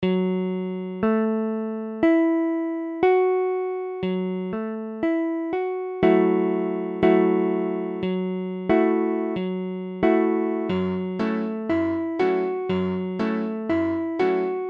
Gbm7m7 : accord de Sol b�mol mineur septi�me Mesure : 4/4
Tempo : 1/4=60
II_Gbm7.mp3